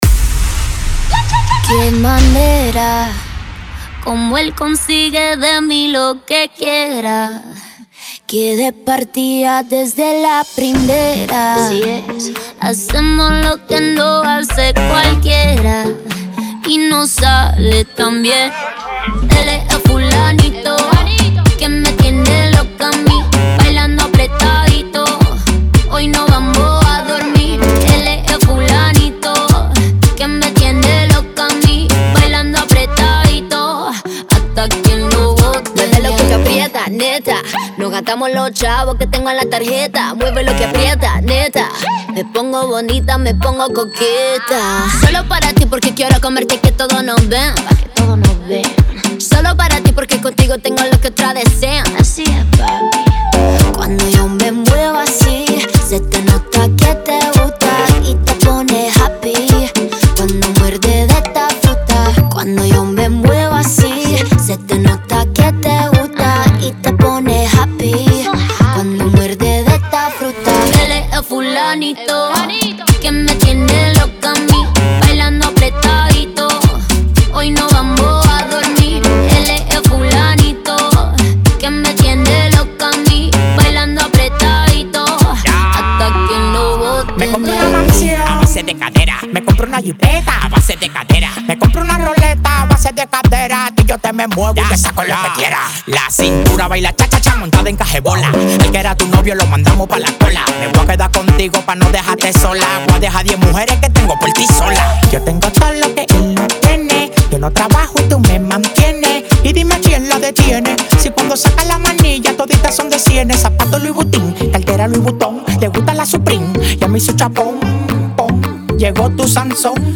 Genre: Mambo.